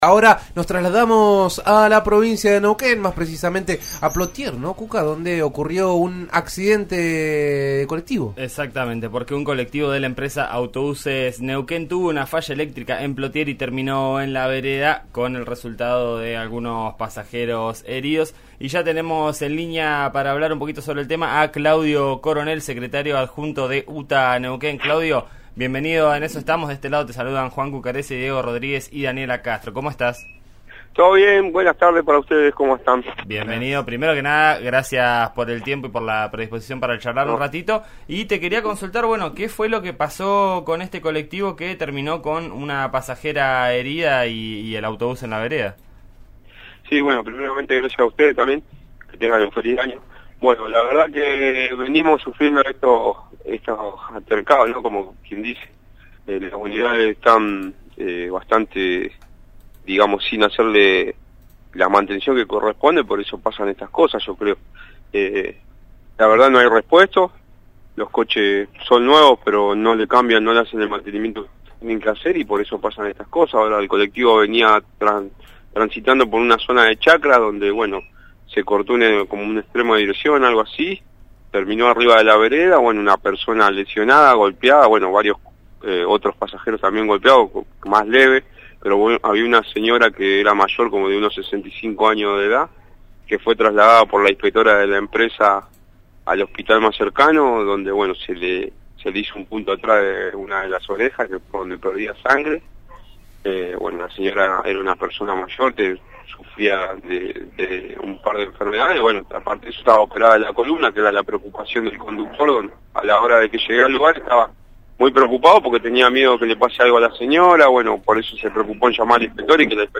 en diálogo con «En eso estamos» por RÍO NEGRO RADIO: